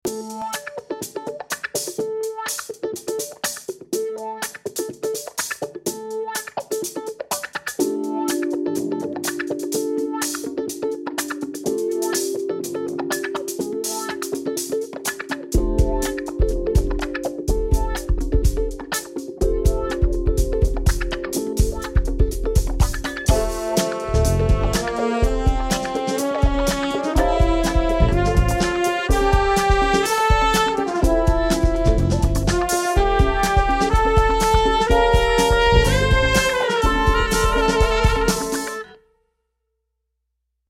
wah-wah (from video game)